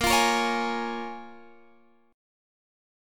A#m7 chord